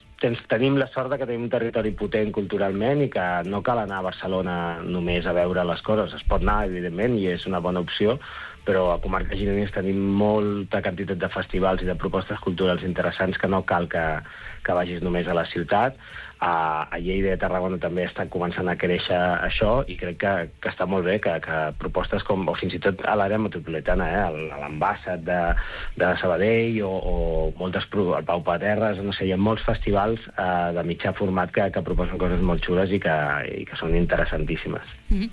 Entrevistes SupermatíSupermatí